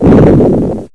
rumble1.ogg